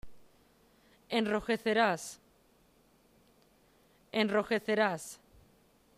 > am Anfang eines Wortes --> stark gerrolt
rojecerás, alrededor --->stark gerrolt